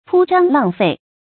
鋪張浪費 注音： ㄆㄨ ㄓㄤ ㄌㄤˋ ㄈㄟˋ 讀音讀法： 意思解釋： 鋪張：講排場。